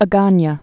(ə-gänyə, ä-gänyä)